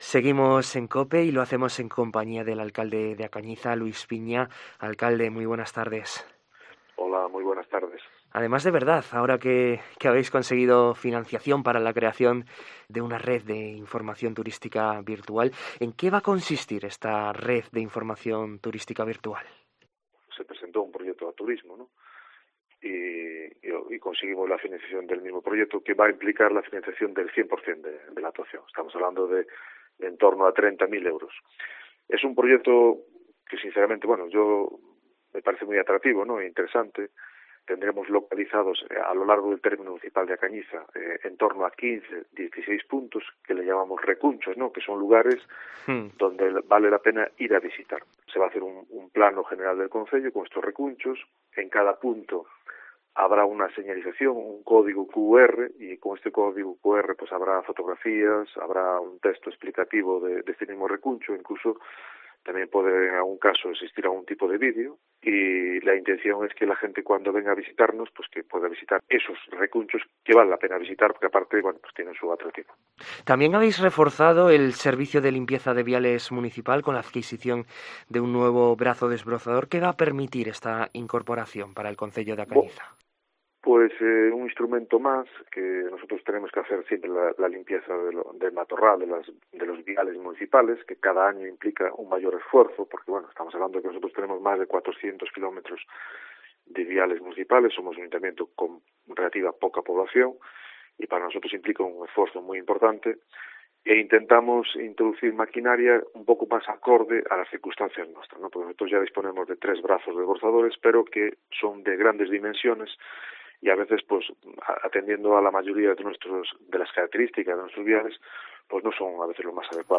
Entrevista a Luis Piña, alcalde de A Cañiza